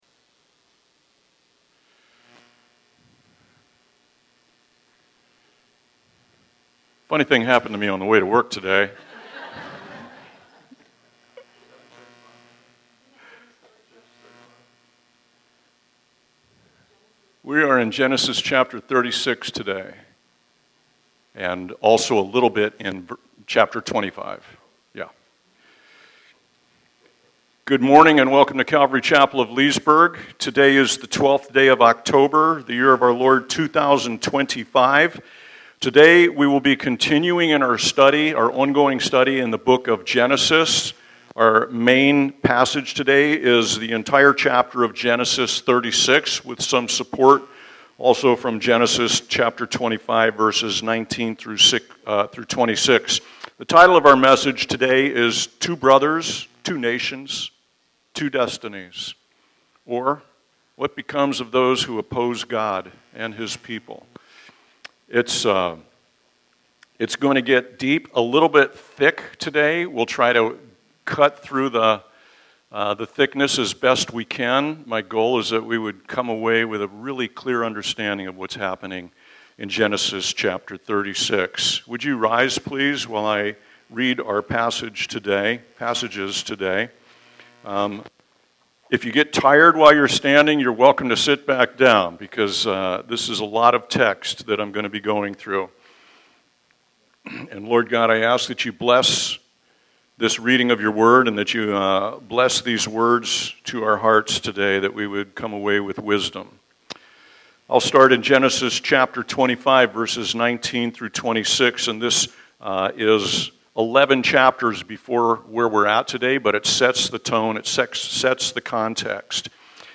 by Calvary Chapel Leesburg | Oct 12, 2025 | Sermons